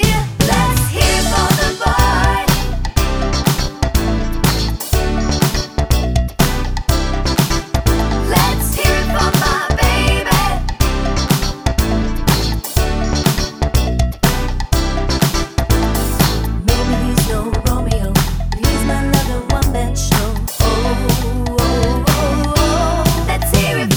With Harmony Pop (1980s) 4:16 Buy £1.50